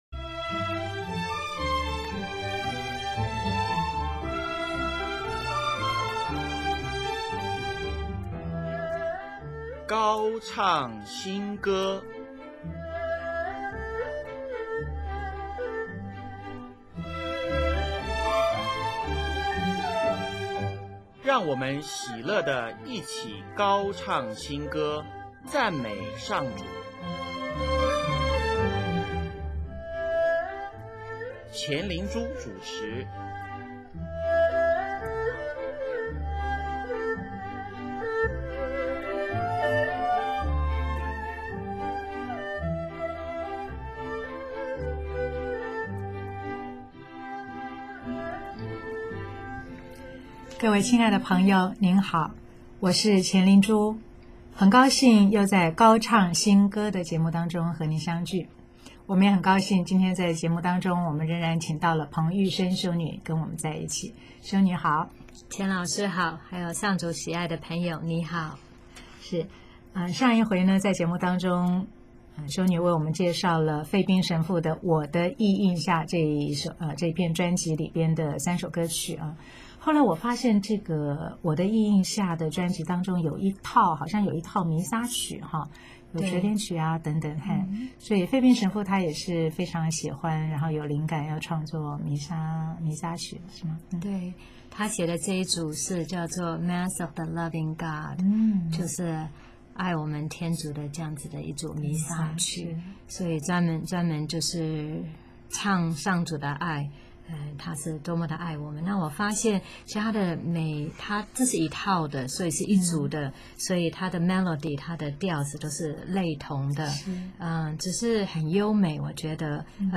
本集播放费宾神父的弥撒组曲Mass of the Loving God，旋律非常优美，带有“求”的意味。